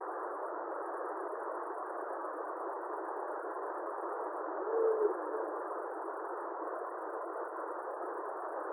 Ūpis, Bubo bubo
StatussDzirdēta balss, saucieni
Piezīmes/Vieta aptuvena, nakts ieraksts.